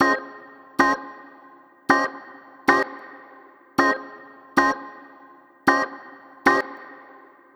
Swingerz 1 Organ-A#.wav